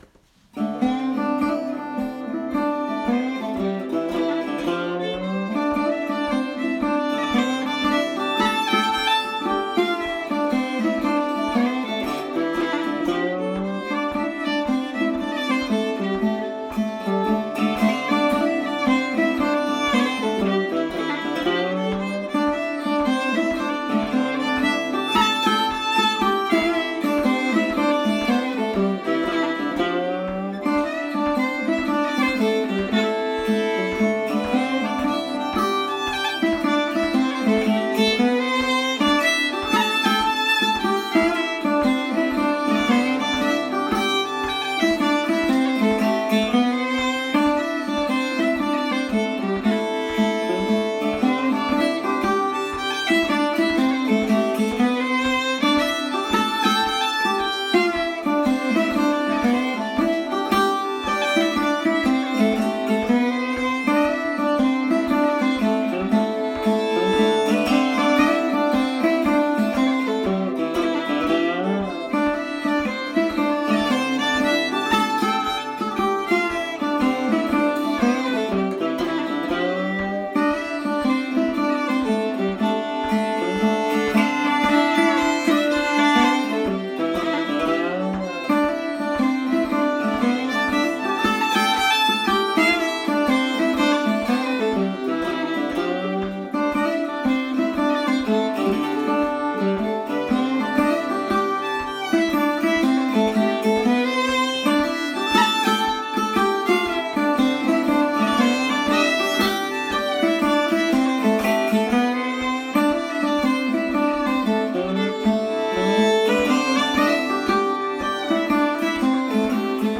Terry Teehan reel set